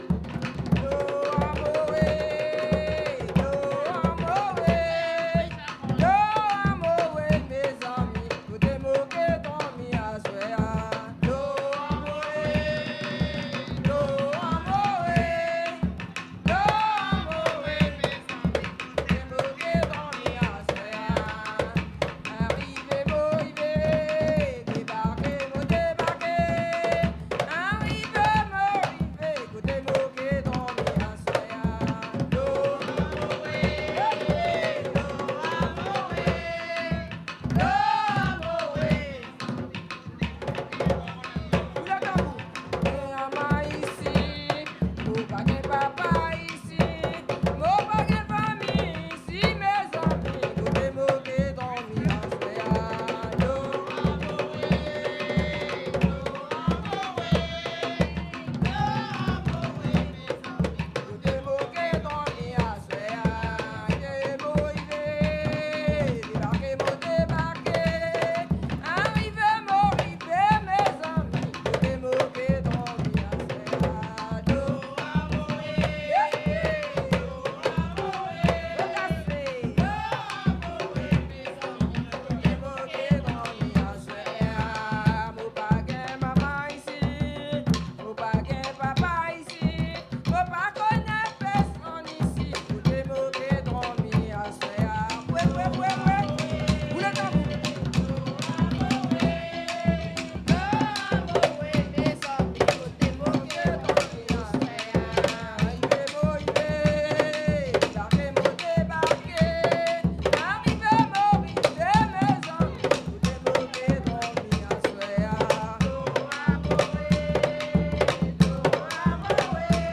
Soirée Mémorial
danse : kasékò (créole)
Pièce musicale inédite